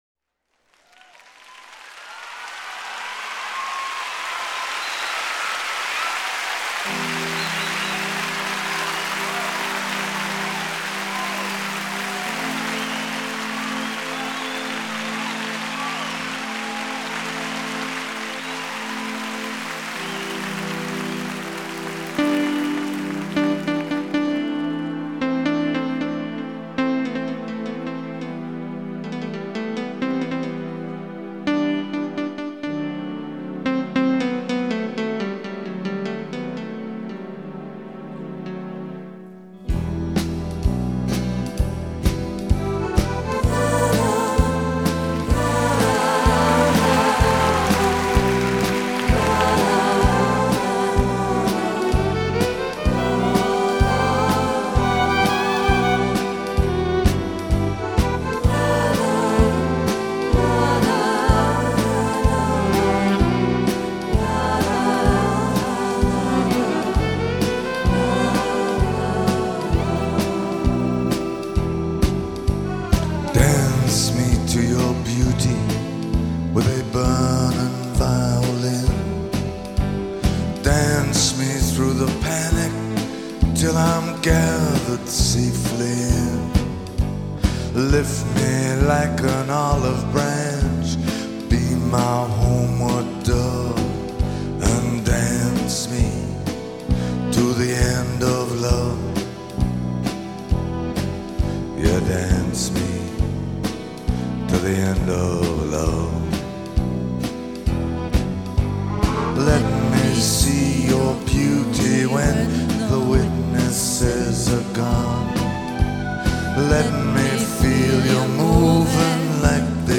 басовое исполнение